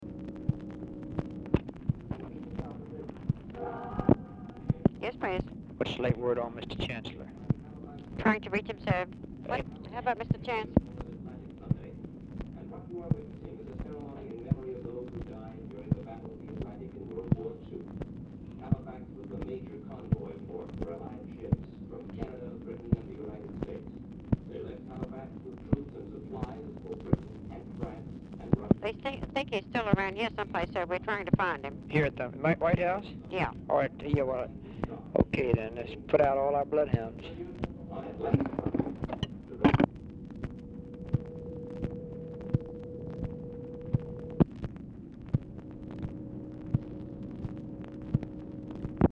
Telephone conversation
TV AUDIBLE IN BACKGROUND
Dictation belt
Mansion, White House, Washington, DC
Speaker 2 TELEPHONE OPERATOR